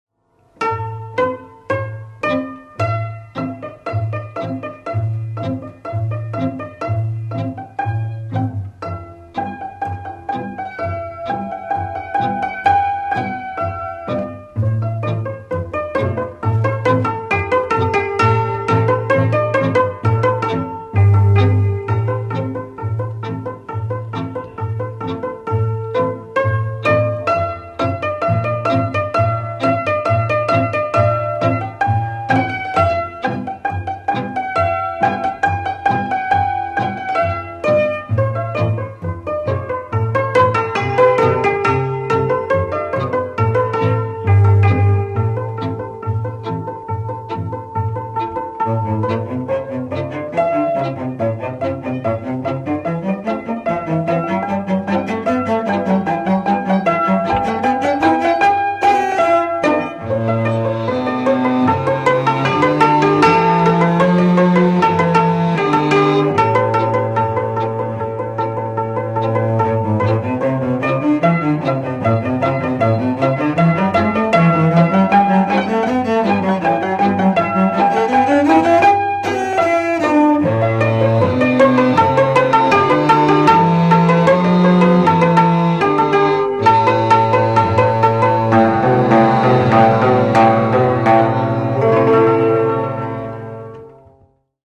Műfaj Csárdás
Hangszer Zenekar
Helység Sopron
Gyűjtő(k) Lajtha László
Cím Pátria, magyar népzenei gramofonfelvételek.